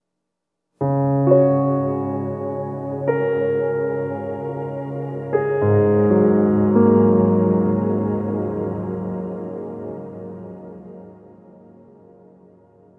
Reverb Piano 08.wav